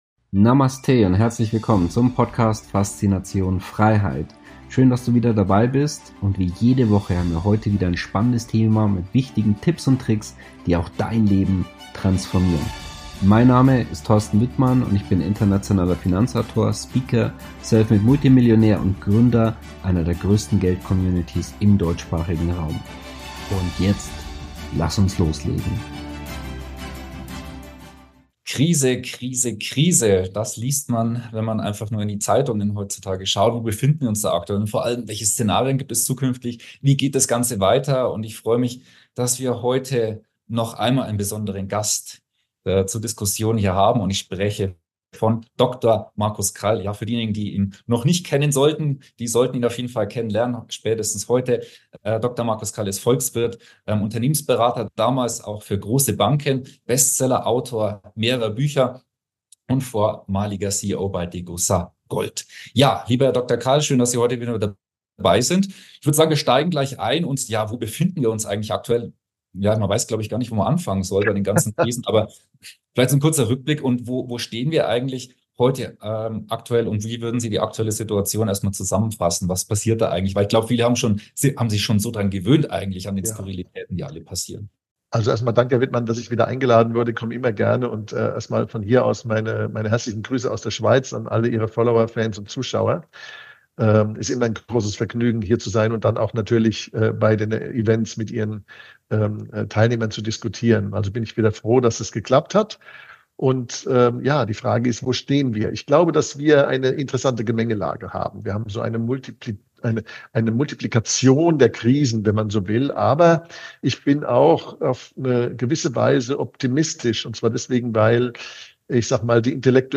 Im heutigen Interview haben wir noch einmal Dr. Markus Krall zu Gast.